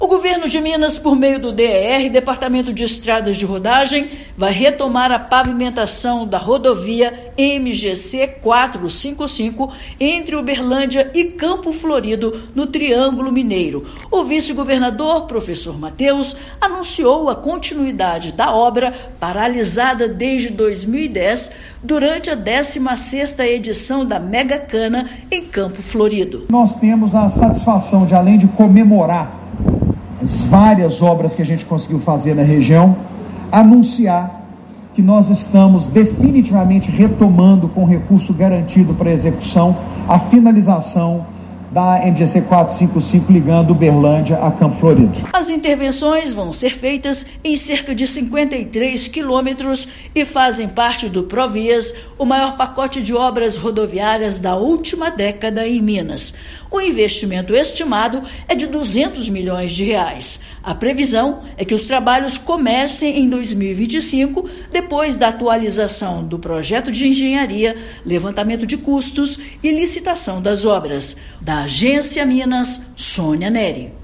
Vice-governador anuncia prosseguimento do asfaltamento da rodovia, que havia sido interrompido em 2010. Ouça matéria de rádio.